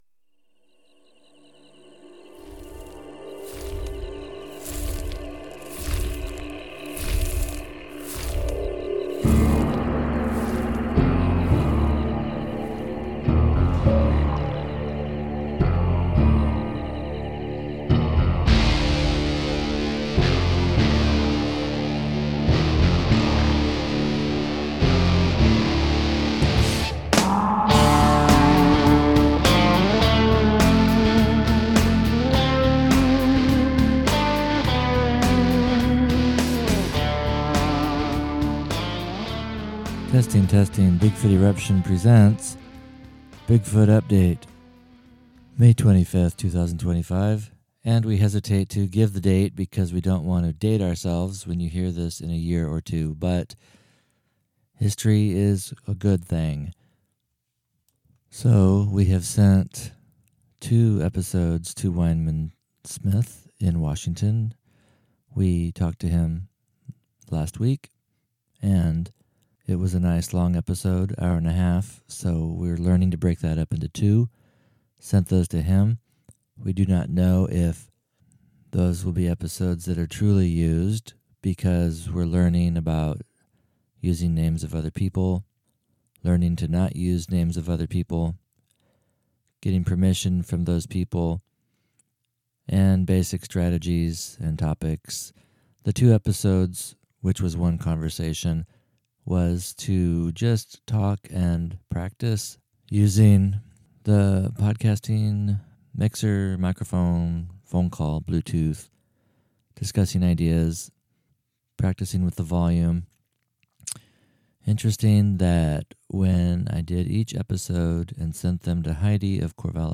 Still on a quest to get our mixer settings accurate for making our volume and the incoming guest volume match, it was a chance to continue tweaking the settings.
We say this because it was a solo episode by our administration, with no guest.